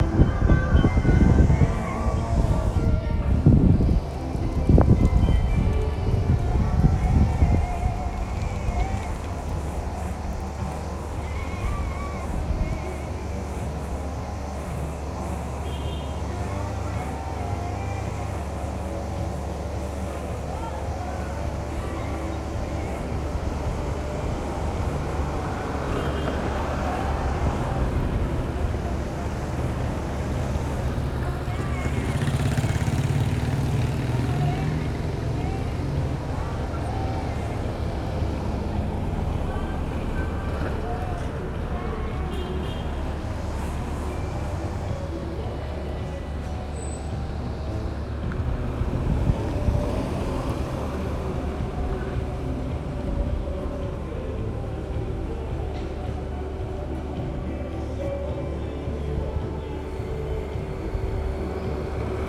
Paisaje sonoro Barrio Escalante SAN JOSÉ